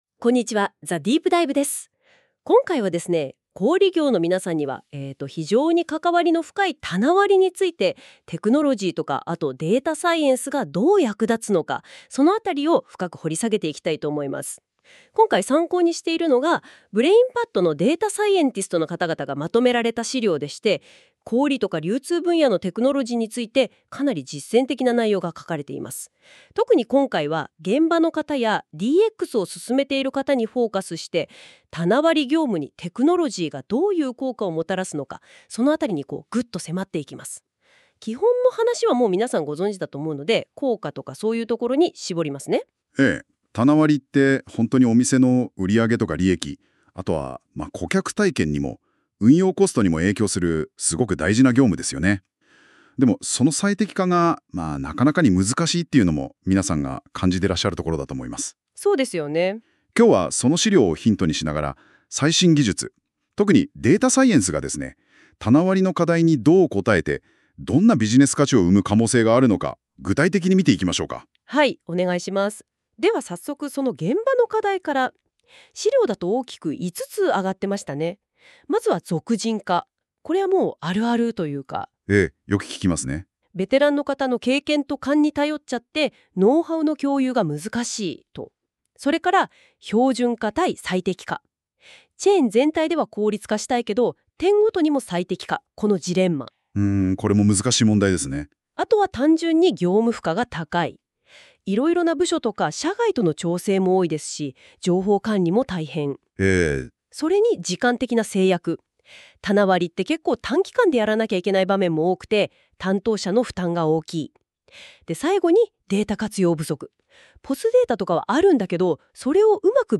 本記事の内容は、GoogleのNotebookLMを利用して作成した音声版でもお聞きいただけます。 生成AIによって自動生成された音声のため、多少の違和感や表現のゆらぎがありますが、通勤中などの「ながら聞き」にぜひご活用ください。